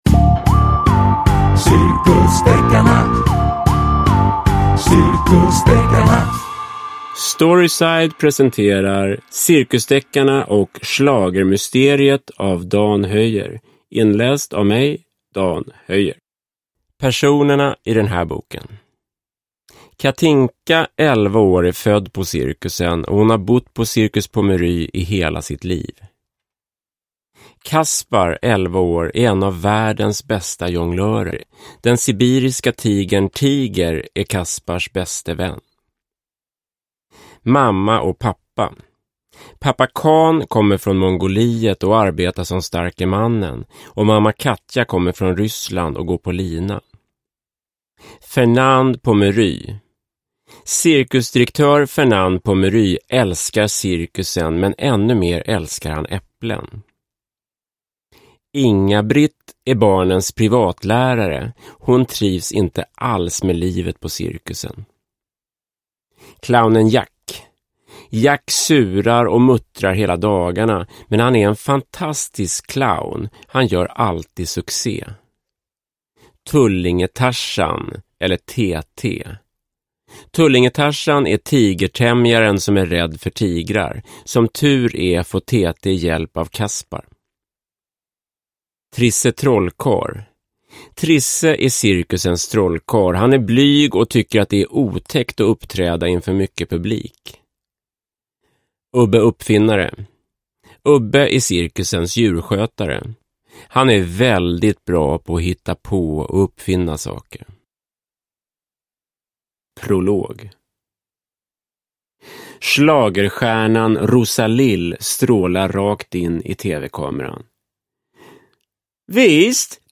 Cirkusdeckarna och schlagermysteriet – Ljudbok – Laddas ner